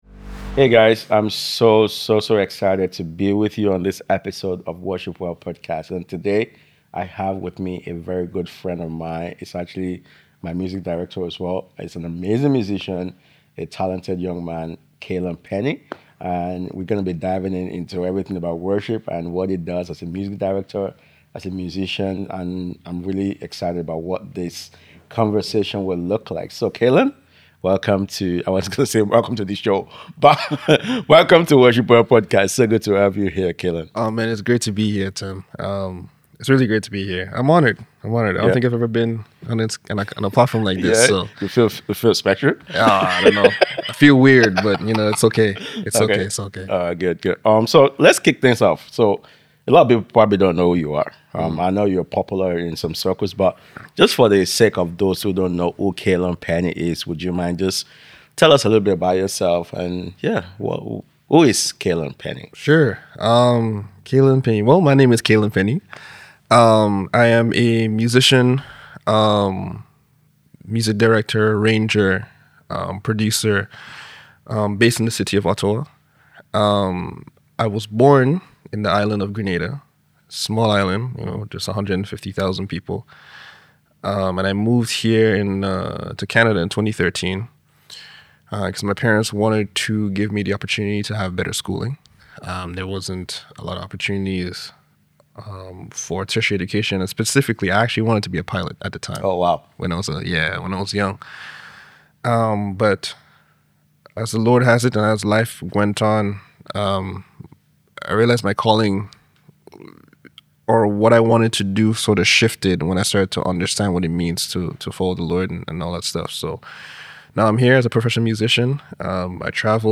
a powerful and transparent conversation